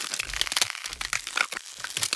FreezeLoop.wav